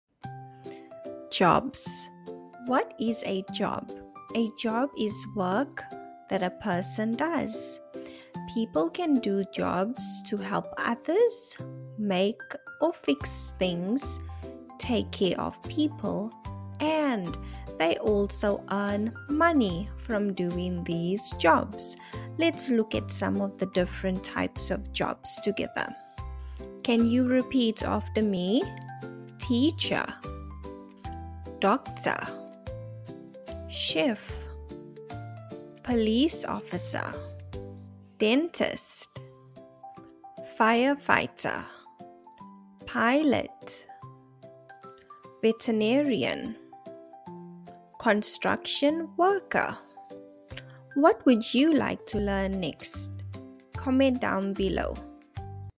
English Vocabulary Lesson